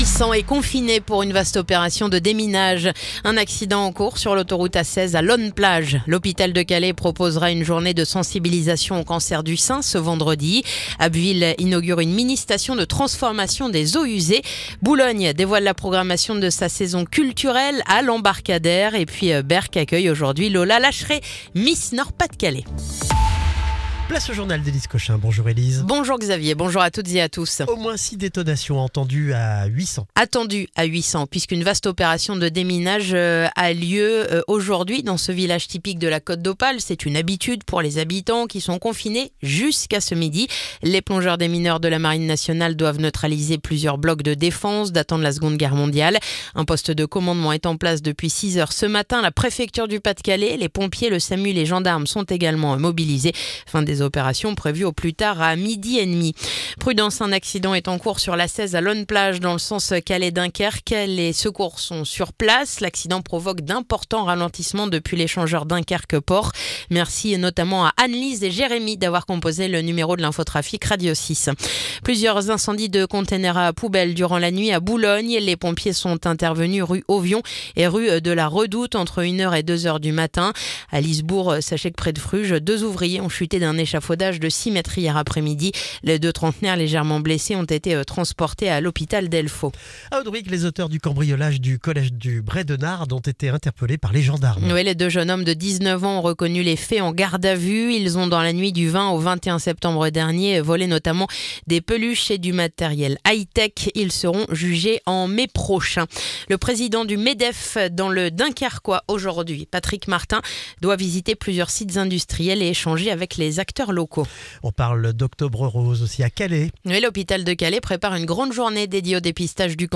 Le journal du mercredi 8 octobre